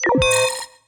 UIBeep_Alert.wav